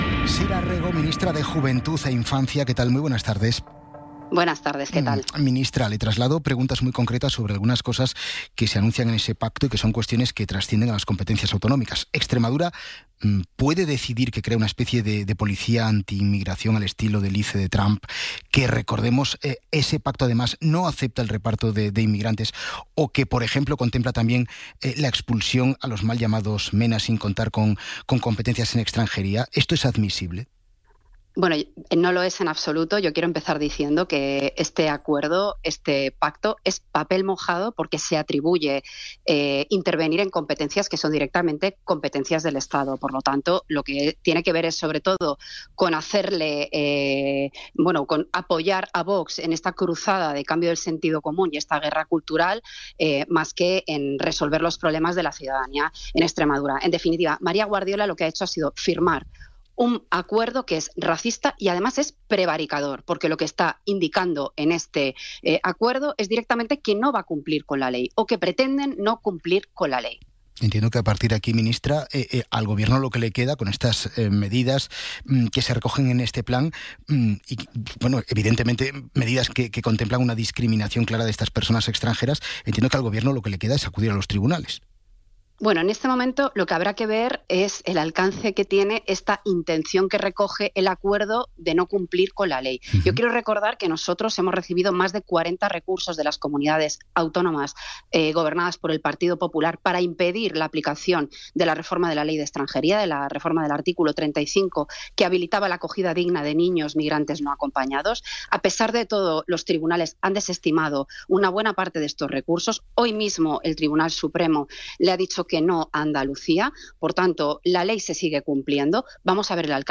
'Hora 14' es el informativo líder del mediodía.